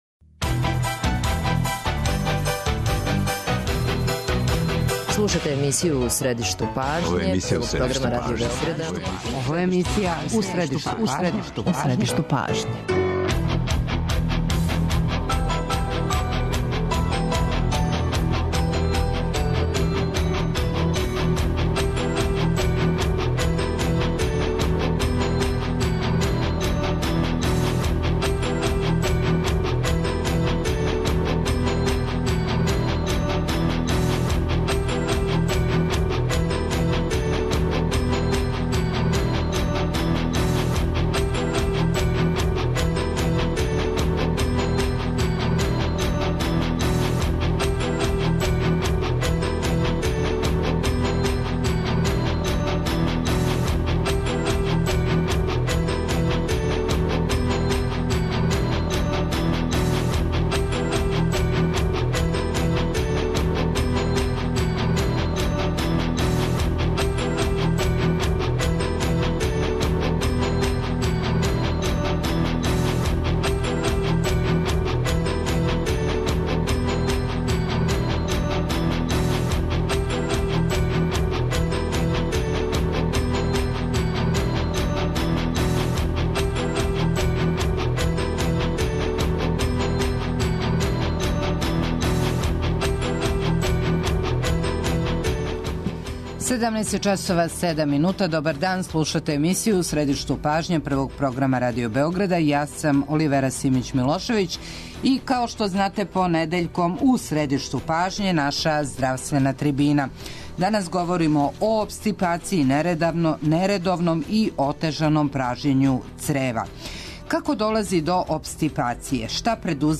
У здравственој трибини говоримо о опстипацији, нередовном и отежаном пражњењу црева.